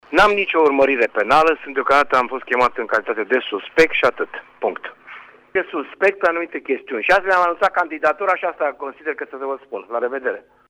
Primarul municipiului Tg.Mureș a declarat, în urmă cu câteva minute, pentru RTM, că procurorii anticorupție nu au declanșat urmărirea penală împotriva sa, ci are doar calitatea de suspect în acest dosar: